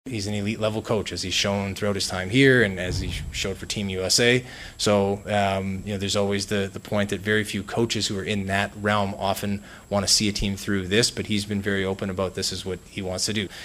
Penguins president of hockey operations Kyle Dubas took questions for over half an hour yesterday in his season-ending news conference, and while he is certain he has the team on the right track, he hinted it might be another year before fans see the sort of Penguins Stanley Cup-contending team they are used to.